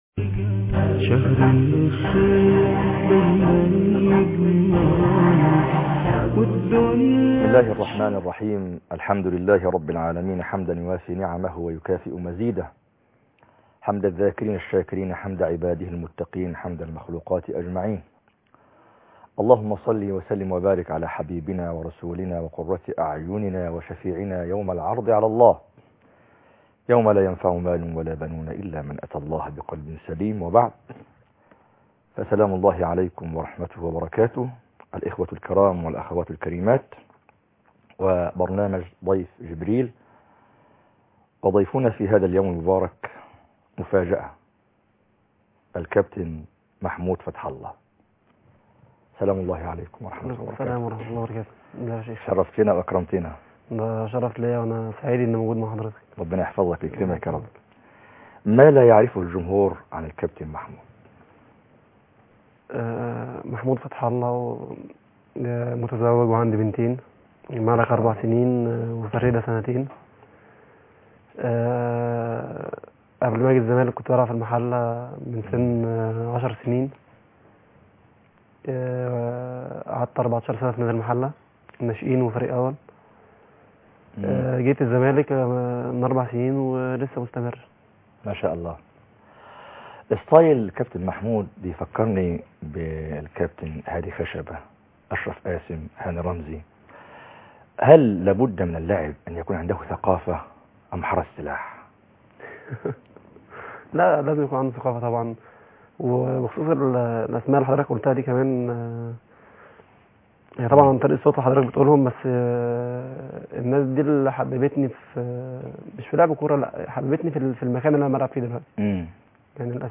لقاء مع اللاعب محمود فتح الله (11/8/2011) ضيف جبريل - قسم المنوعات